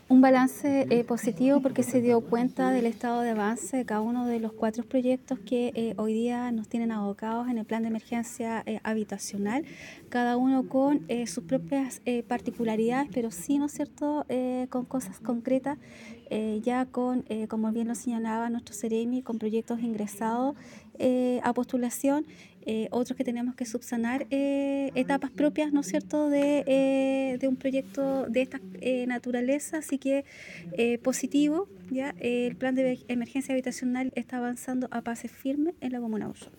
La Delegada Presidencial Provincial, Claudia Pailalef, destacó el avance de los proyectos para Osorno, ante lo que señaló que se está cumpliendo el compromiso adquirido por las autoridades gubernamentales.